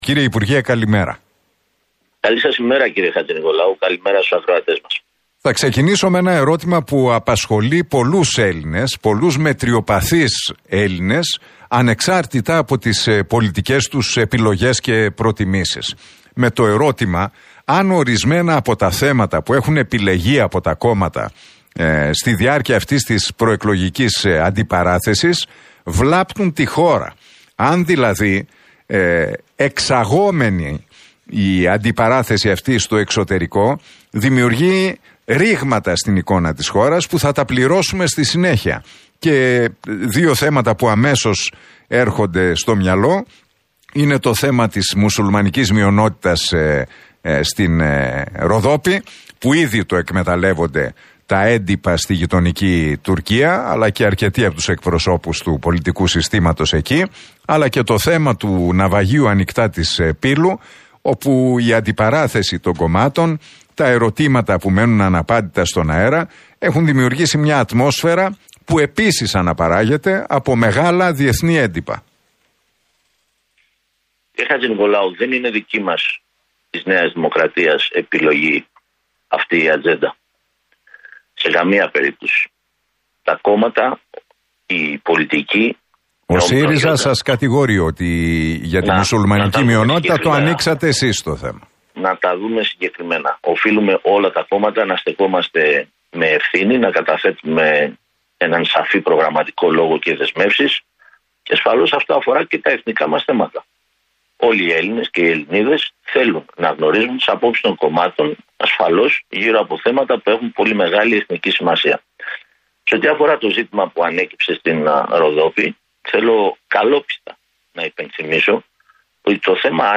Σε ό,τι αφορά στο ζήτημα που ανέκυψε στην Ροδόπη, θέλω καλόπιστα να υπενθυμίσω ότι το θέμα άνοιξε από δηλώσεις στελεχών του ΣΥΡΙΖΑ αμέσως μετά τις εκλογές» δήλωσε ο Τάκης Θεοδωρικάκος, μιλώντας στην εκπομπή του Νίκου Χατζηνικολάου στον Realfm 97,8.